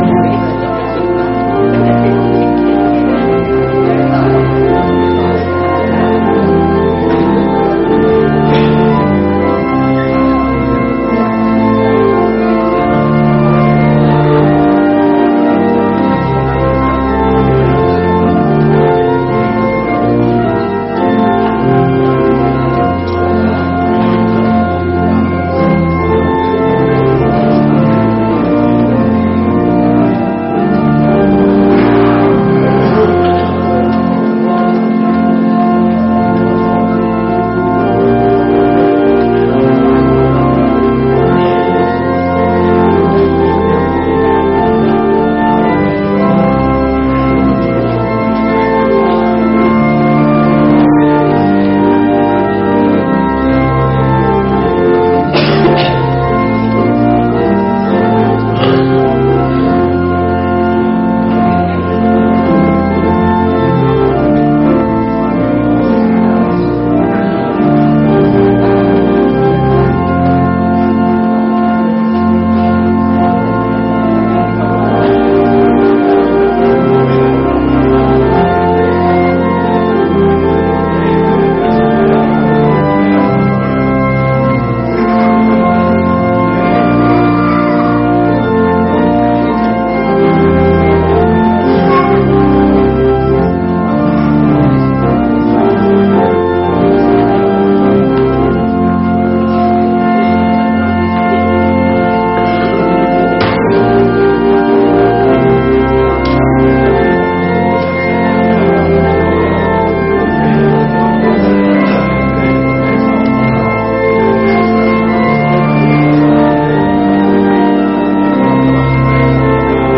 Gottesdienst Sonntag 12.10.2025 | Evangelisch-altreformierte Kirchengemeinde Laar
Wir laden ein, folgende Lieder aus dem Evangelischen Gesangbuch mitzusingen: Lied 177, 2, Lied 596, 1-3, Psalm 105, 4, Psalm 89, 1+5+6, Lied 395, 1-3, Lied 170, 1-4
Gottesdienst.mp3